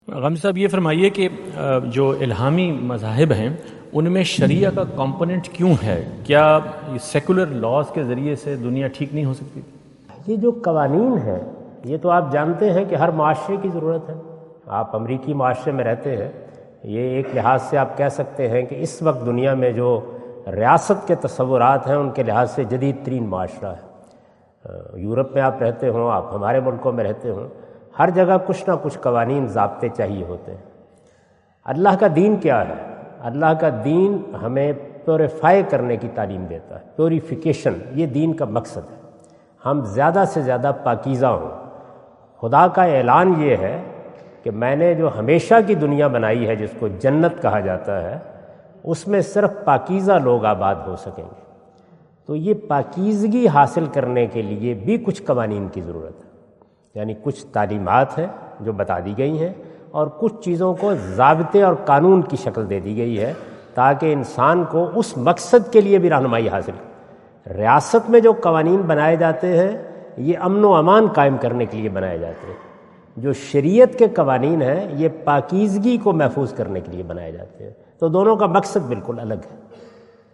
Category: English Subtitled / Questions_Answers /
Javed Ahmad Ghamidi answer the question about "Do we need religion to run the system of this world?" During his US visit at Wentz Concert Hall, Chicago on September 23,2017.